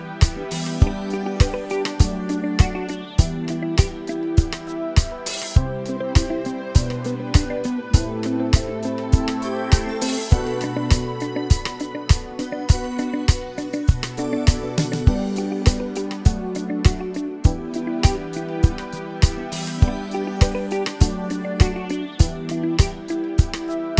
no bass Pop (1980s) 4:22 Buy £1.50